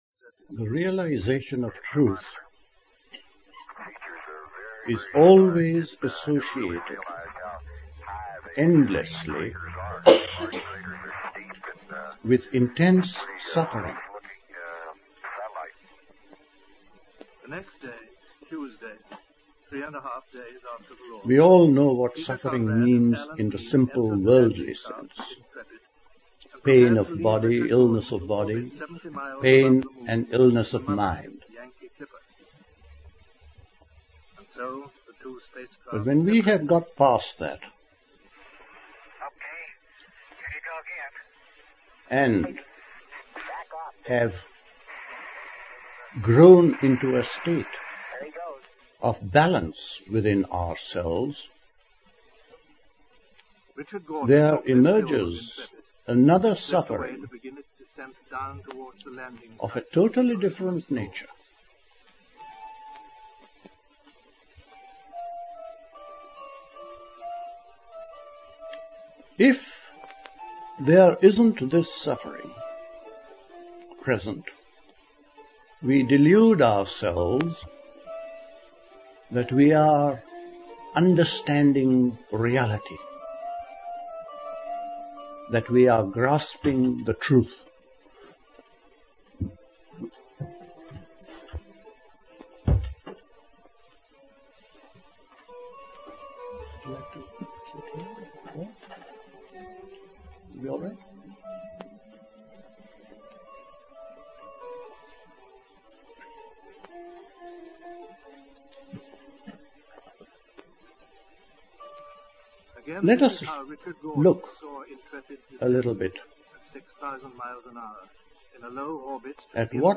A talk
at Dilkusha, Forest Hill, London on 31st January 1970